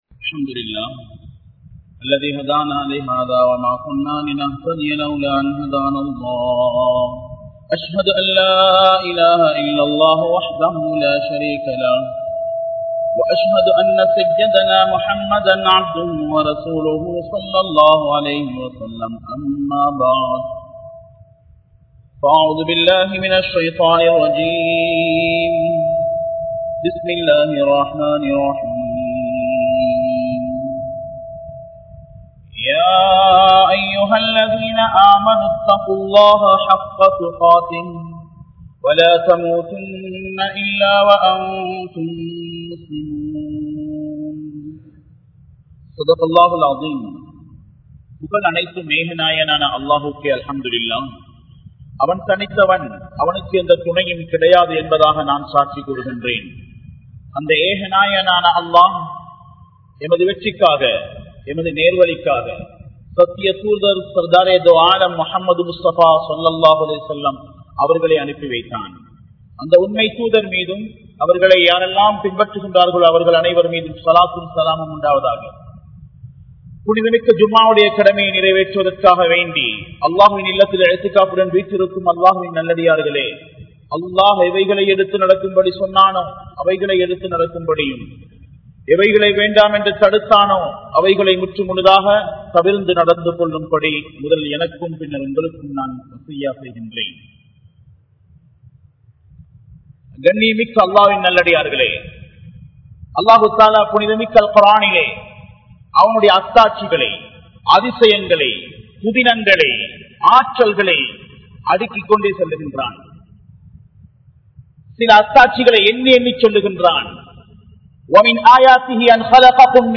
Thirumanagalai Seeralikkum Indraya Muslimkal (திருமணங்களை சீரழிக்கும் இன்றைய முஸ்லிம்கள்) | Audio Bayans | All Ceylon Muslim Youth Community | Addalaichenai
Colombo 12, Aluthkade, Muhiyadeen Jumua Masjidh